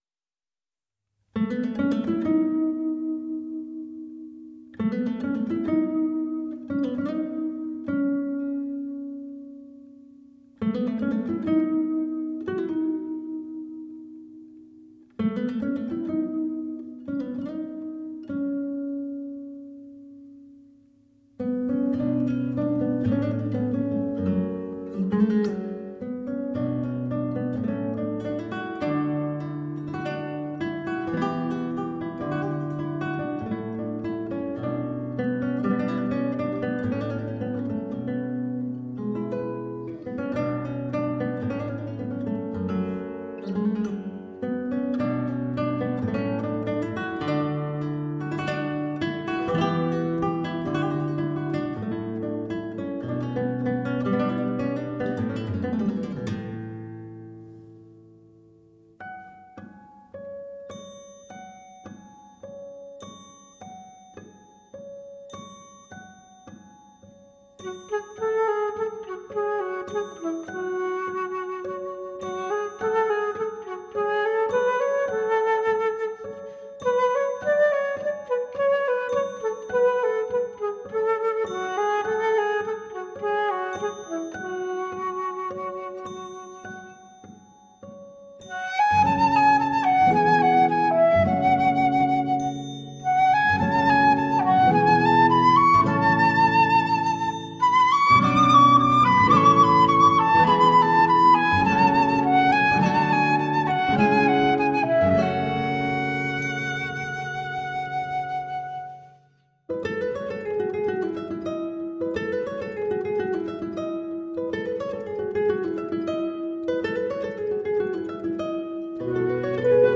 Lively flute/guitar duo.